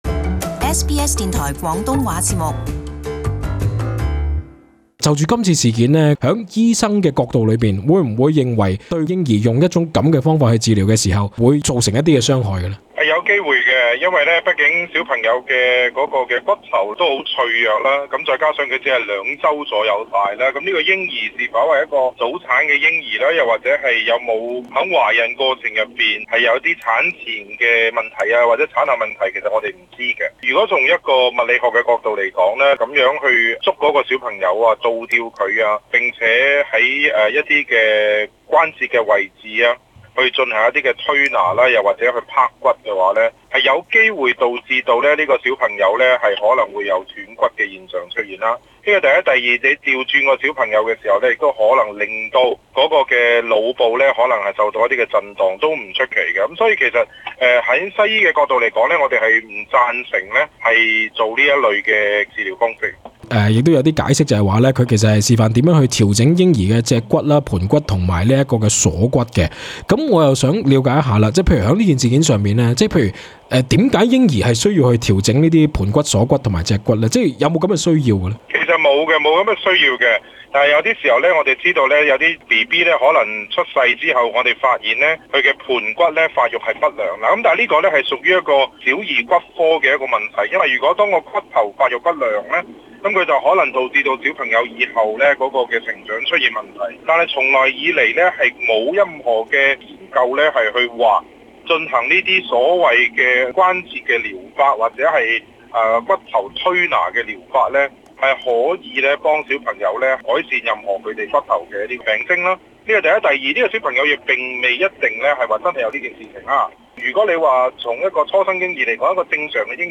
【社區專訪】如何衡量嬰幼兒是否需要進行脊骨按摩？